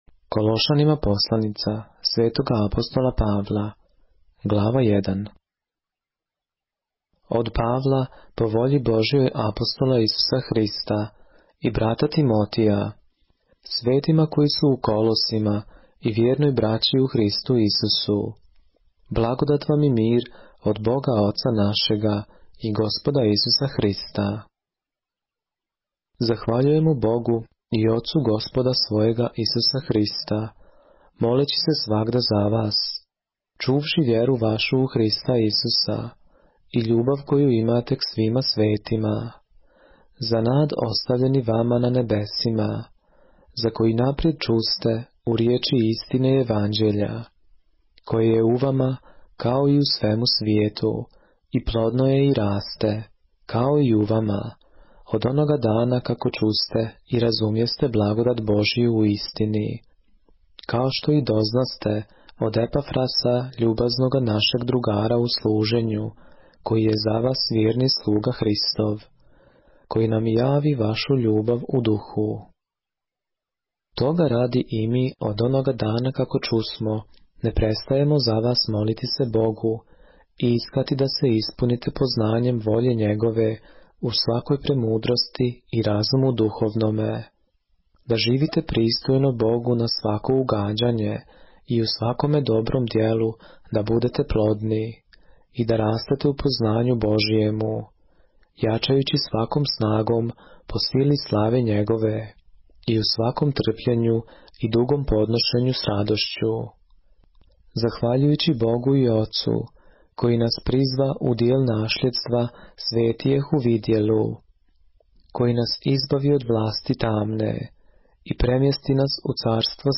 поглавље српске Библије - са аудио нарације - Colossians, chapter 1 of the Holy Bible in the Serbian language